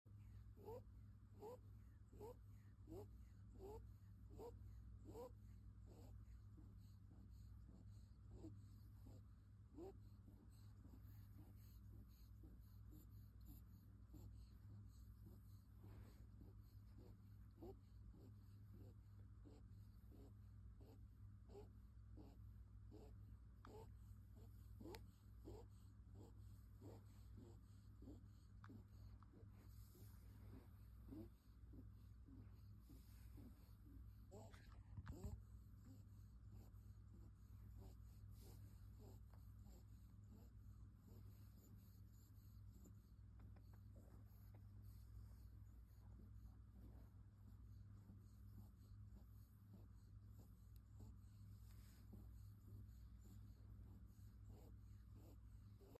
Bunny snores, honks, and sleepy sound effects free download
Bunny snores, honks, and sleepy noises.